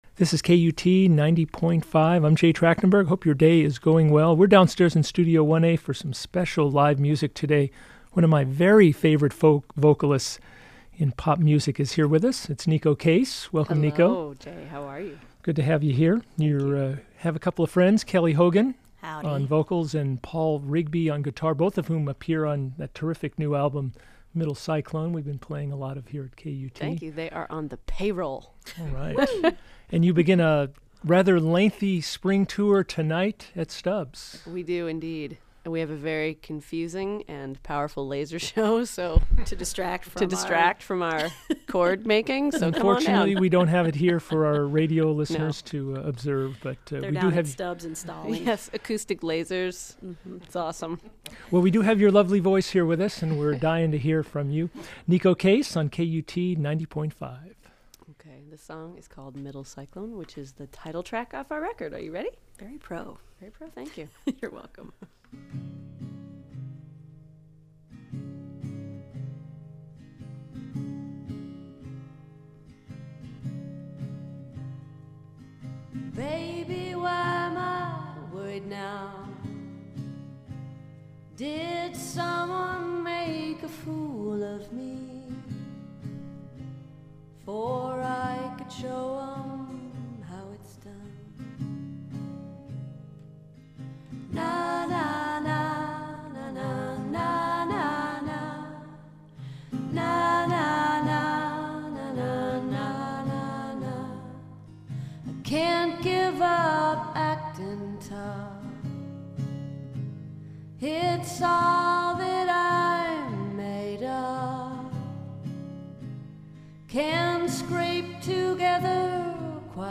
an acoustic session
vocalist
guitarist
and then played it — minus the orchestra, of course.